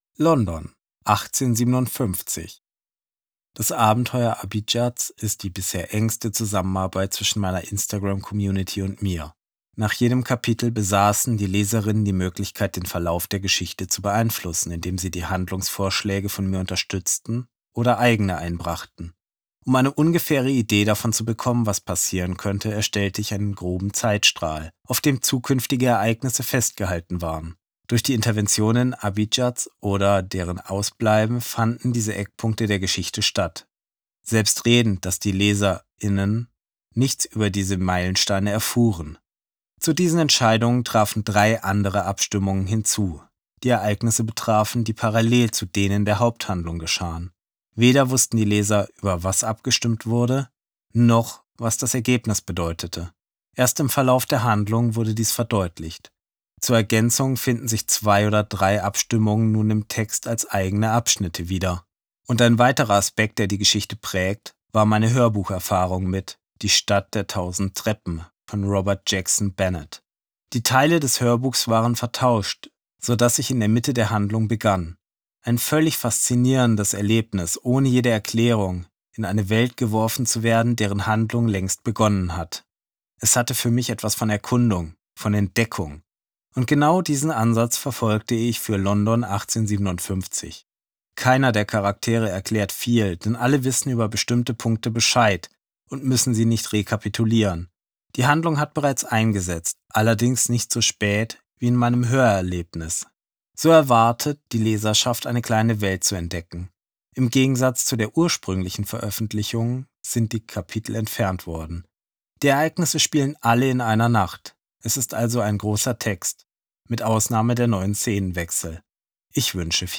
Hörbuch | Fantasy